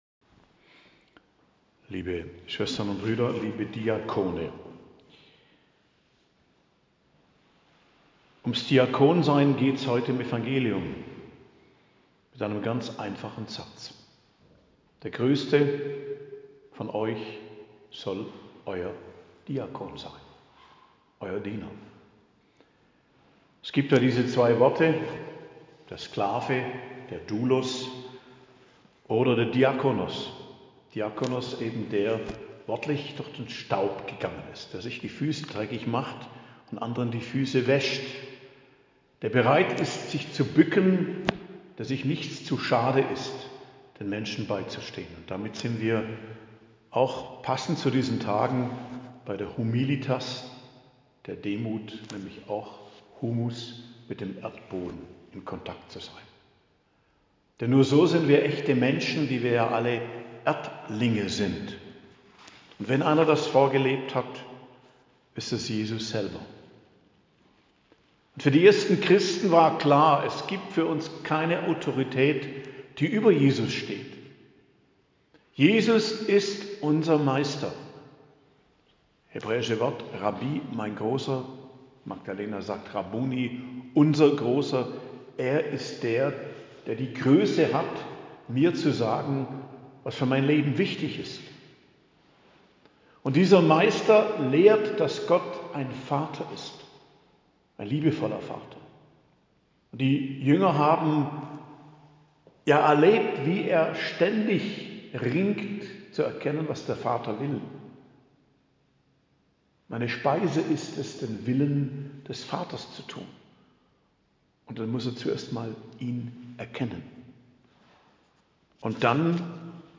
Predigt am Dienstag der 2. Woche der Fastenzeit, 27.02.2024